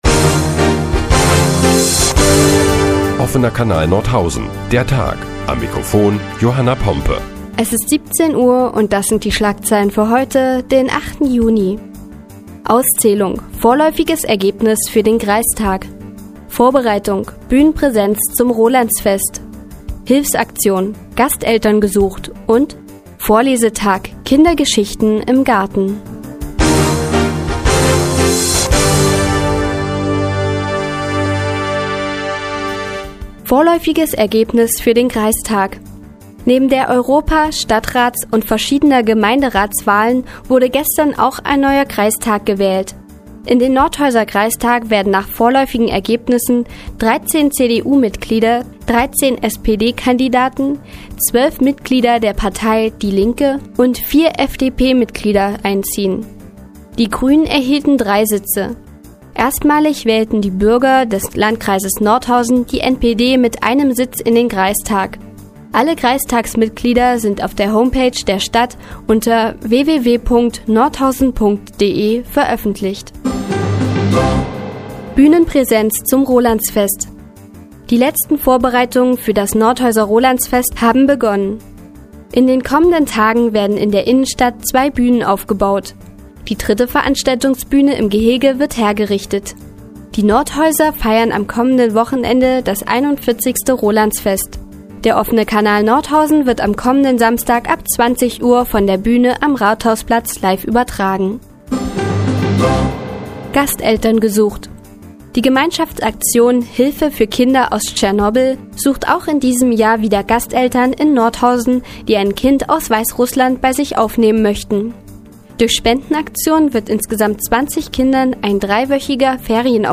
Die tägliche Nachrichtensendung des OKN ist nun auch in der nnz zu hören. Heute geht es unter anderem um das Ergebnis der Kreistagswahl und Hilfe für Kinder aus Tschernobyl.